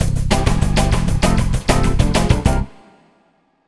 Swinging 60s 2 Full-C.wav